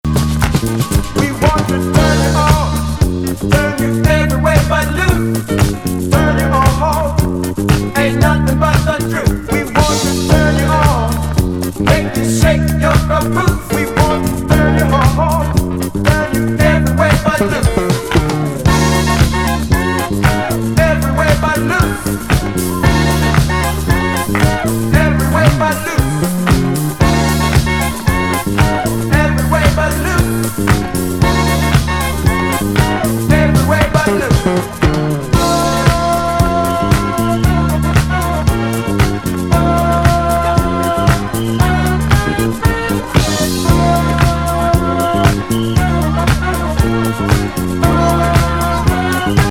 ファンキー・アフロ・ディスコ・